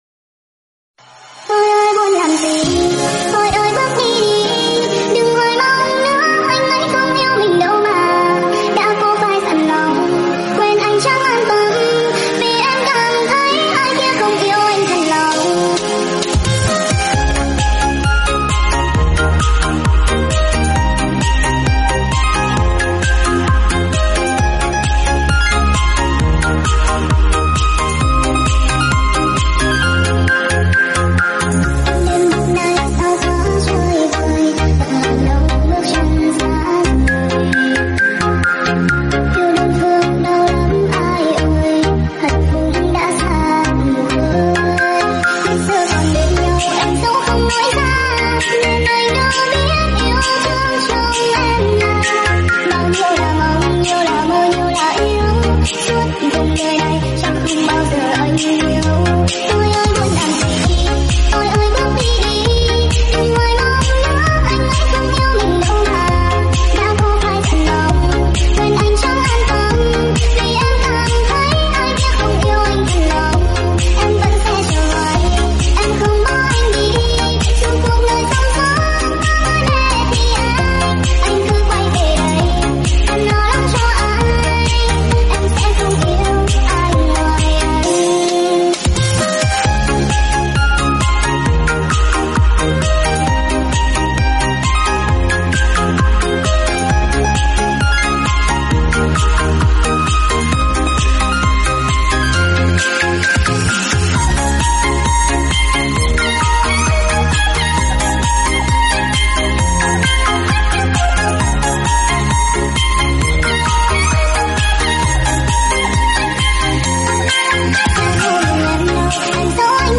Lo Remix